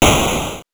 explosion_16.wav